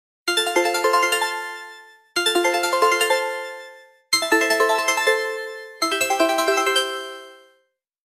Klassisk Telefon, Klassisk, Android